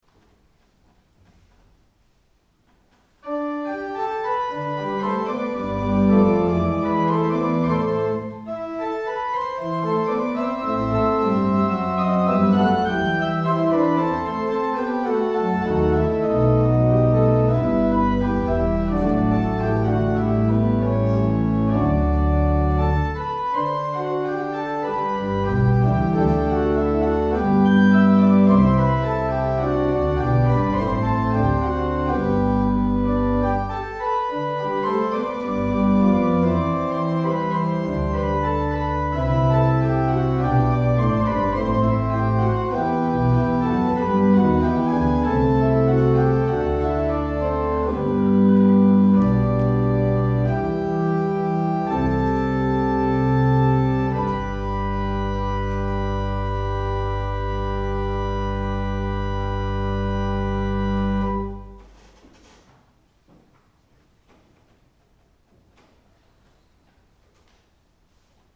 Bordenau Ev.-luth. St. Thomas-Kirche
Stimmung nach Bach-Kellner
Klangbeispiele dieser Orgel